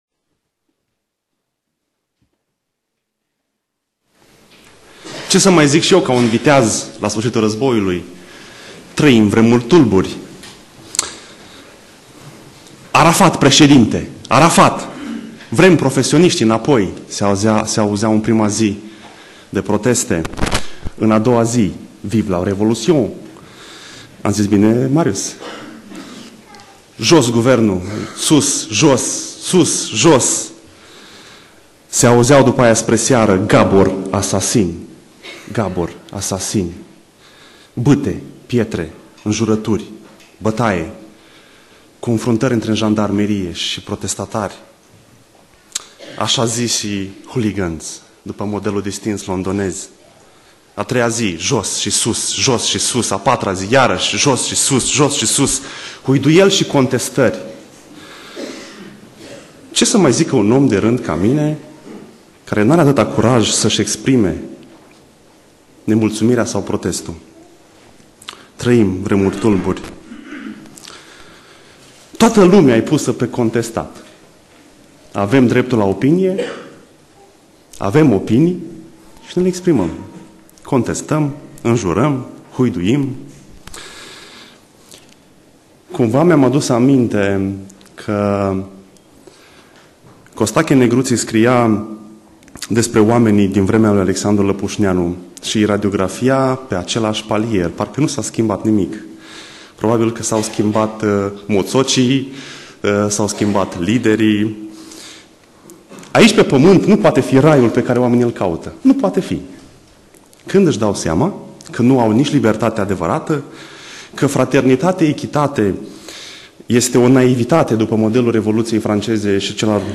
Predica Exegeza - Matei 11